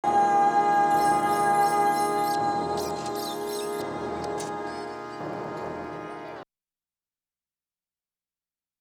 Vocal.wav